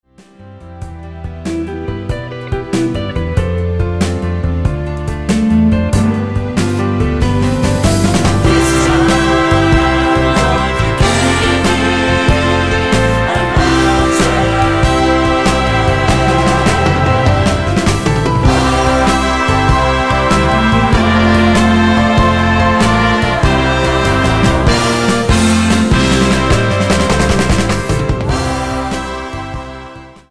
--> MP3 Demo abspielen...
Tonart:C mit Chor